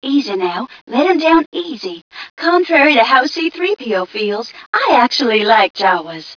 1 channel
mission_voice_tgca059.wav